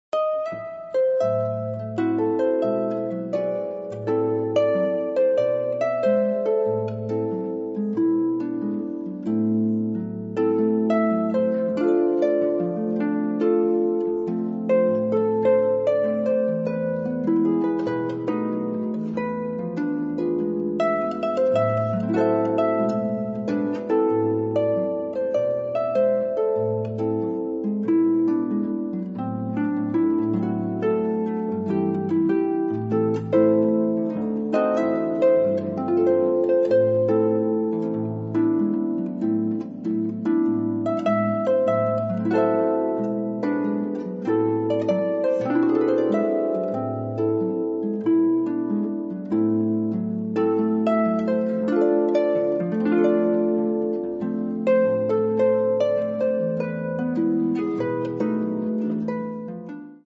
Genre: Pop & Jazz